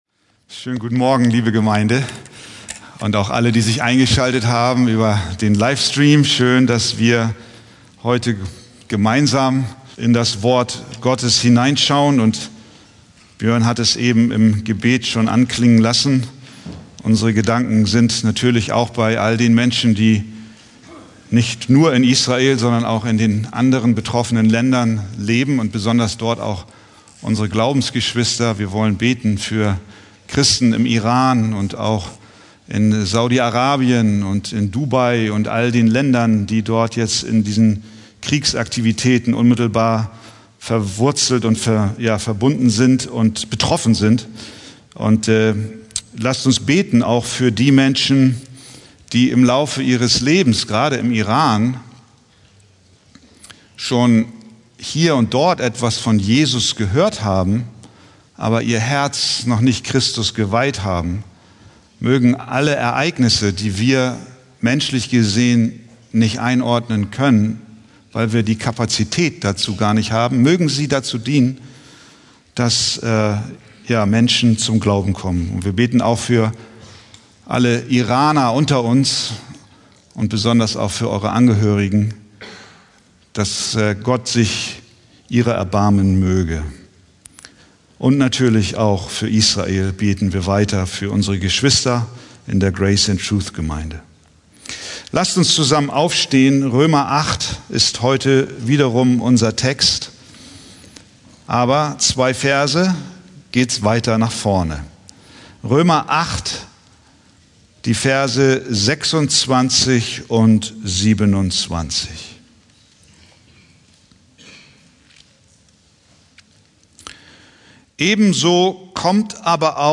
Predigttext: Römer 8,26-27